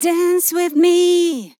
Categories: Vocals Tags: Dance, DISCO VIBES, dry, english, female, LYRICS, me, sample, with
POLI-LYRICS-Fills-120bpm-Fm-16.wav